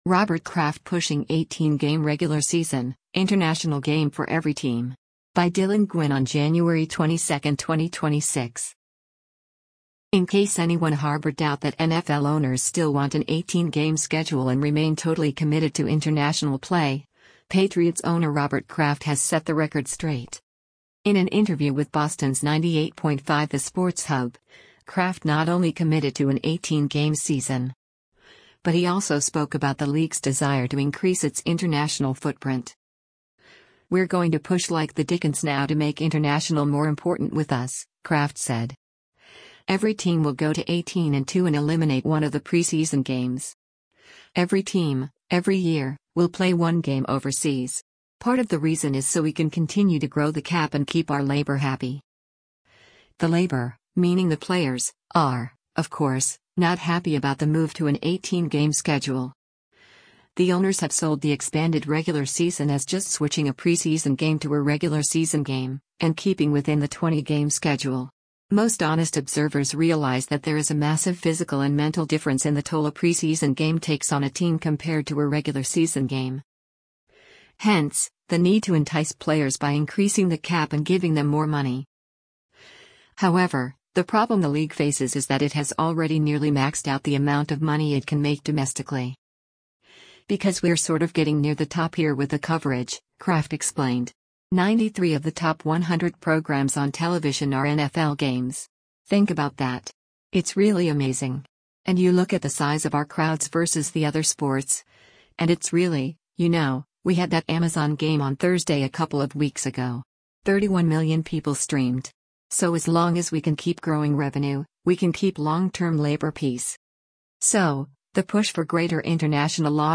In an interview with Boston’s 98.5 The Sports Hub, Kraft not only committed to an 18-game season.